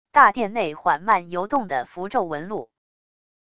大殿内缓慢游动的符咒纹路wav音频